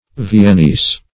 Viennese \Vi`en*nese"\, a.